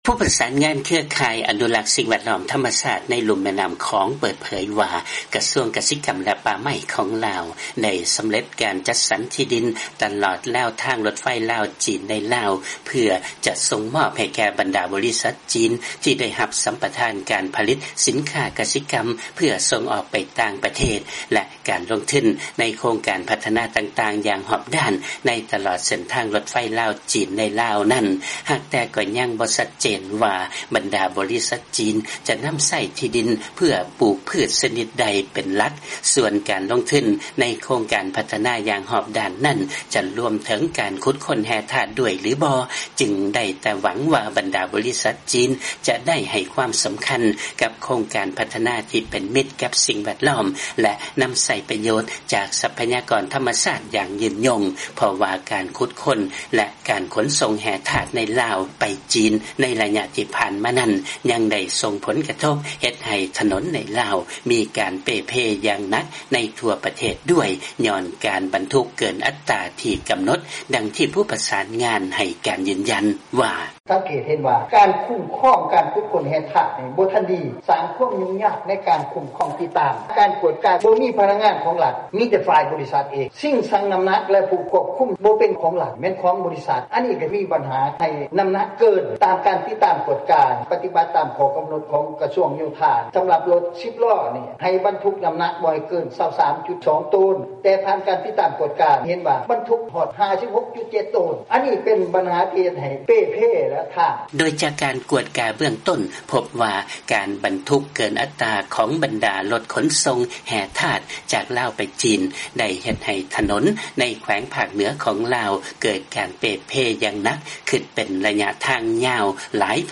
ຟັງງລາຍງານ ການບັນທຸກເກີນອັດຕາຂອງພວກລົດຂົນສົ່ງແຮ່ທາດຈາກລາວ ໄປຈີນ ໄດ້ສົ່ງຜົນກະທົບ ເຮັດໃຫ້ເສັ້ນທາງຄົມມະນາຄົມໃນລາວ ເກີດການເປ່ເພຢ່າງໜັກ